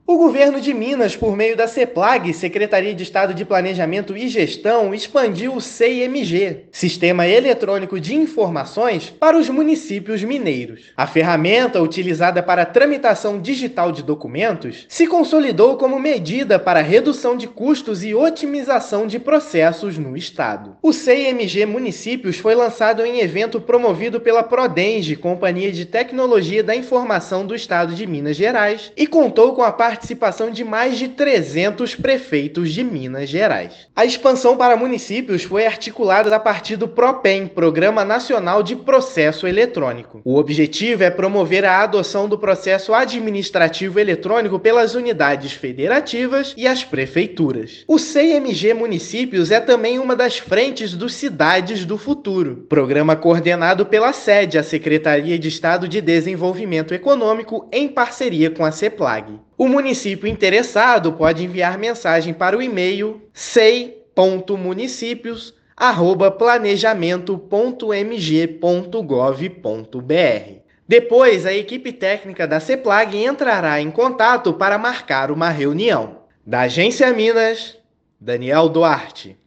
Sei!MG Municípios, coordenado pela Seplag-MG, foi apresentado durante o Workshop Inova 2024, que contou com a presença de mais de 300 prefeitos mineiros. Ouça a matéria de rádio: